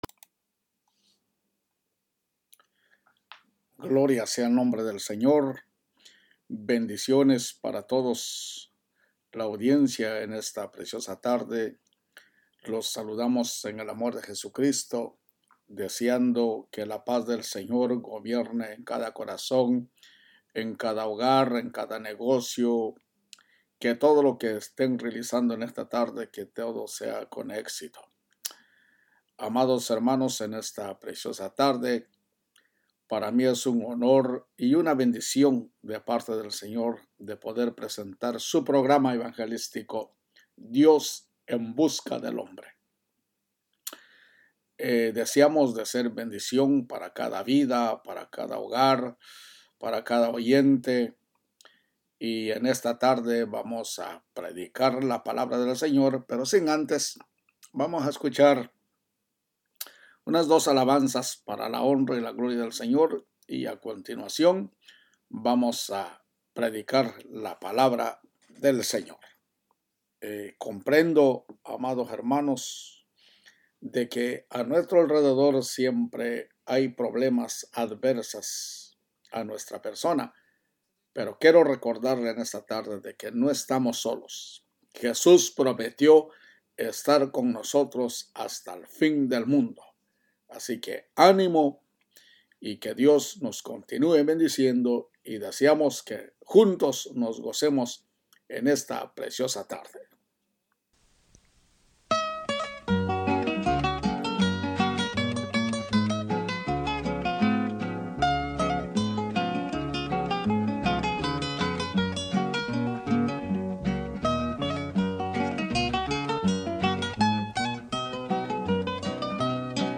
¿VEREMOS A NUESTROS AMADOS OTRA VEZ? PREDICA #10
VEREMOS-A-NUESTROS-AMADOS-OTRA-VEZ-PREDICA-10.mp3